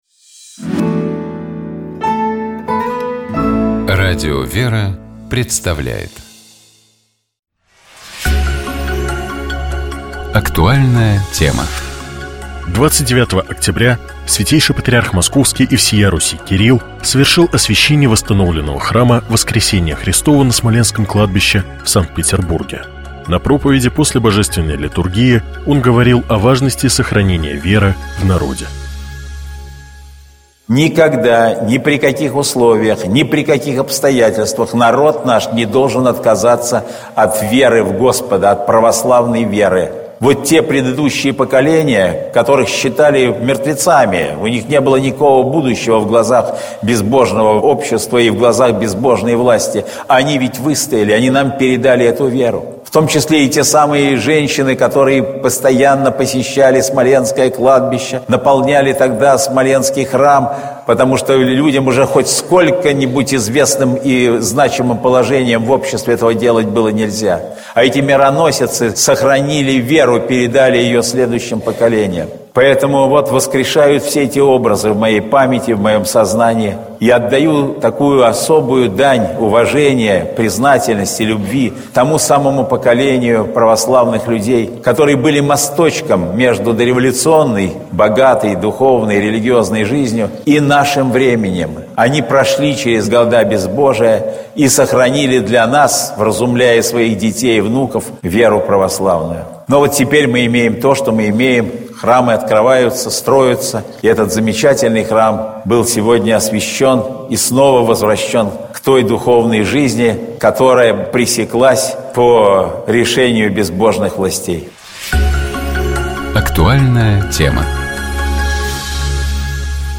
На проповеди после Божественной литургии он говорил о важности сохранения веры в народе.